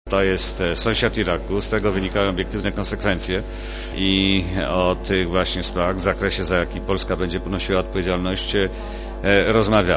Mówi Włodzimierz Cimoszewicz (40Kb)